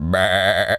sheep_baa_bleat_05.wav